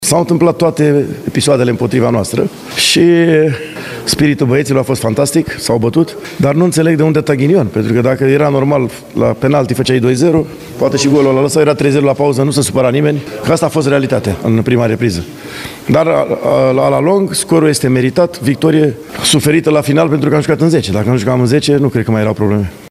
În replică, antrenorul feroviarilor clujeni, Dan Petrescu, a văzut din punctul său de vedere dezavantajele și ghinioanele cu care s-a confruntat: